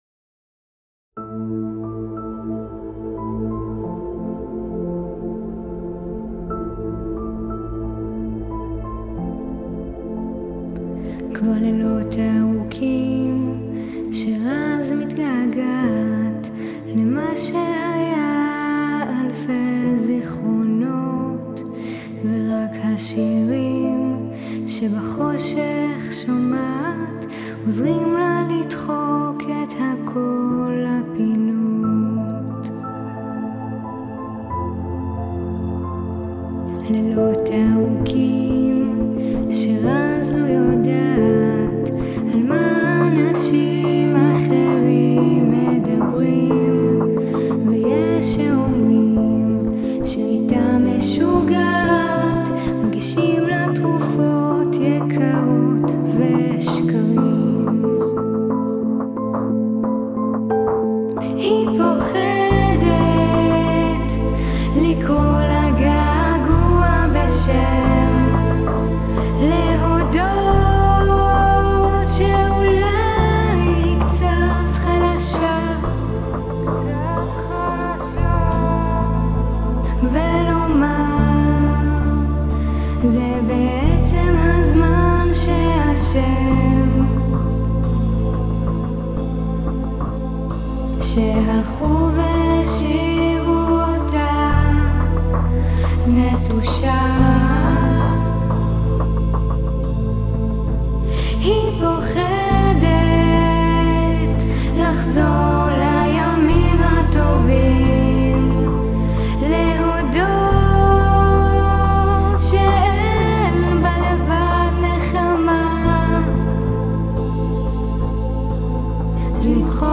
1. יש יותר מדי תהודה לקול של המבצעת.
2. הבאס (לפחות בהתחלה) מודגש מאוד.